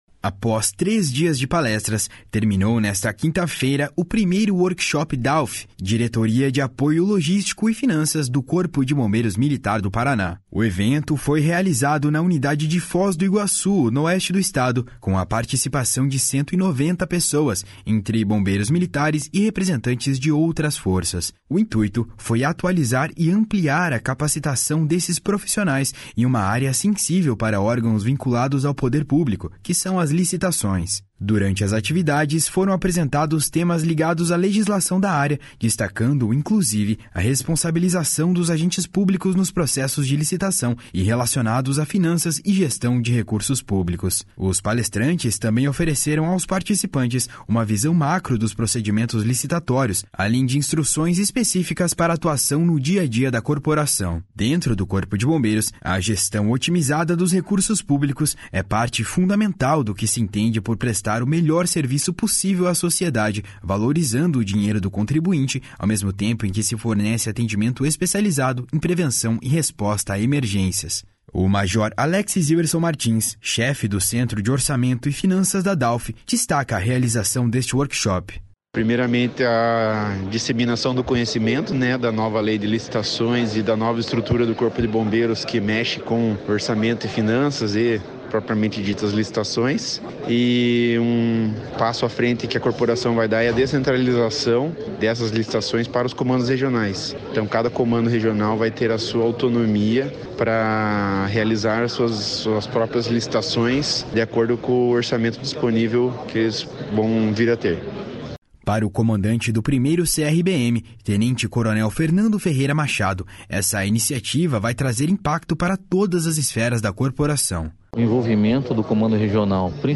WORKSHOP DE APOIO LOGISTICO E FINANÇAS.mp3